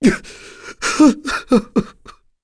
Clause-Vox_Sad1.wav